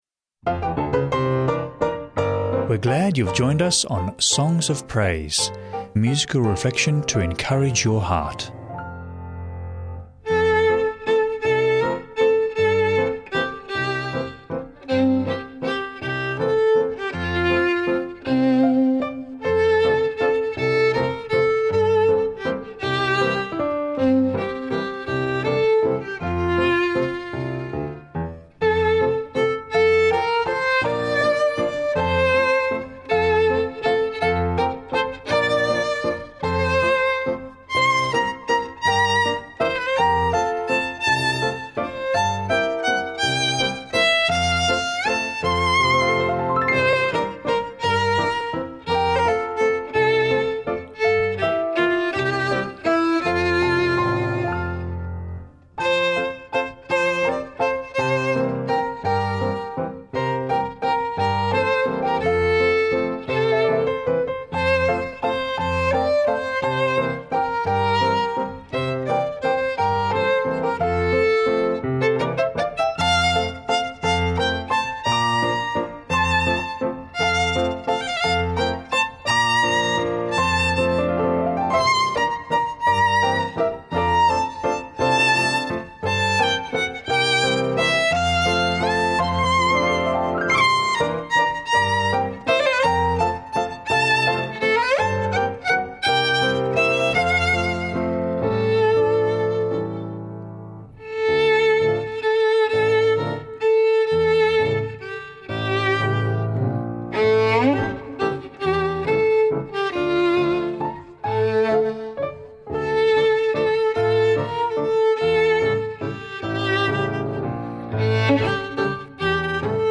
uplifting Christian hymns and worship music
Book Reading